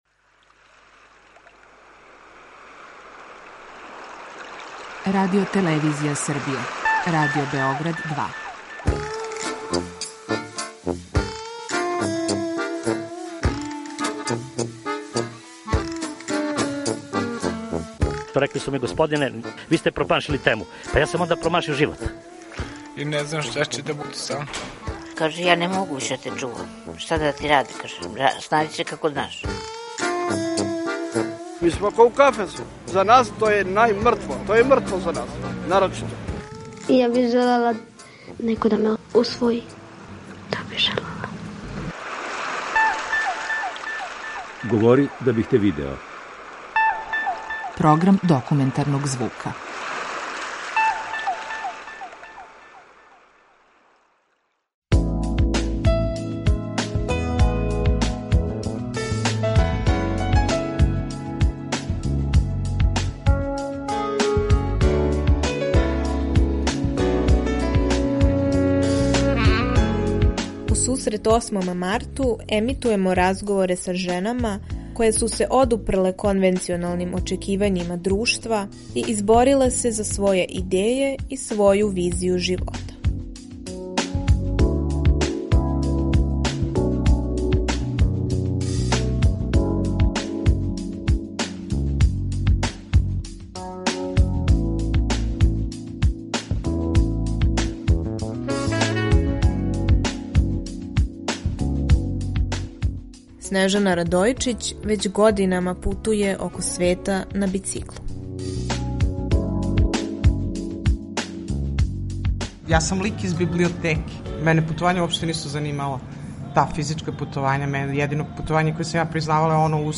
Документарни програм
У данашњој репортажи, у сусрет Осмом марту, емитујемо разговоре са женама које су се одупрле конвенционалним очекивањима друштва и избориле се за своје идеје и своју визију живота..